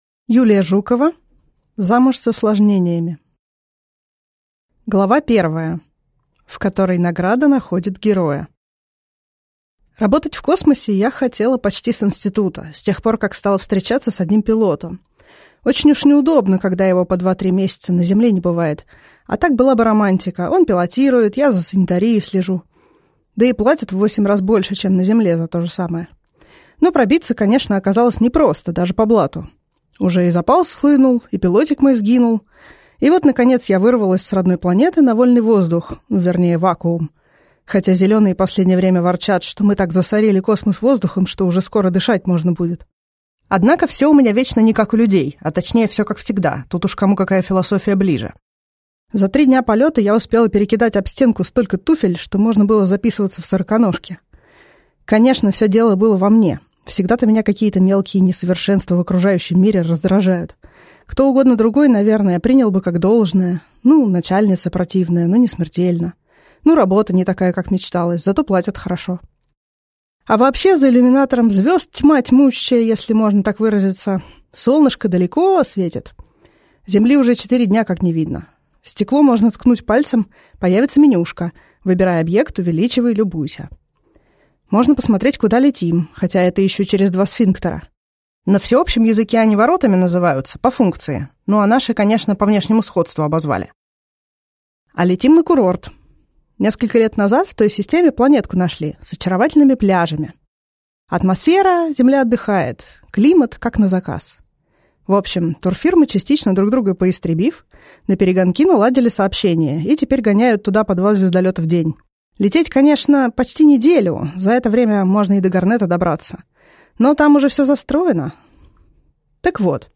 Аудиокнига Замуж с осложнениями | Библиотека аудиокниг